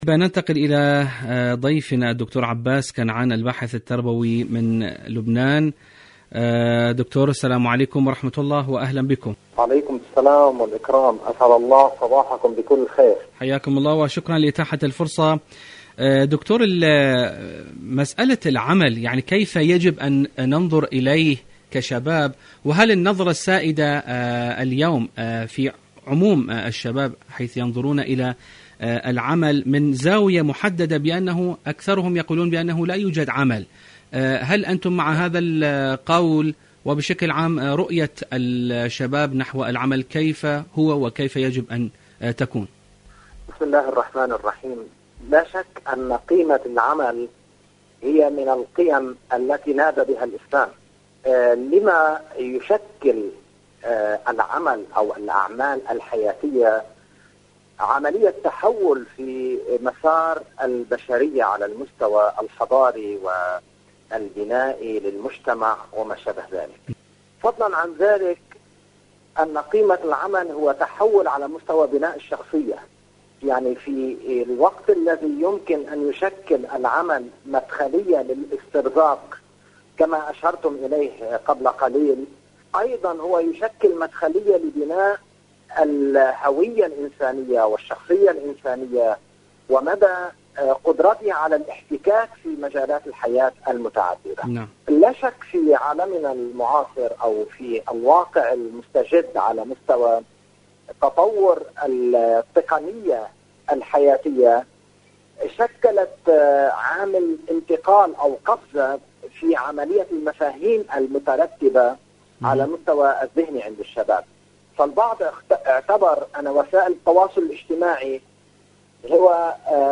إذاعة طهران-دنيا الشباب: مقابلة إذاعية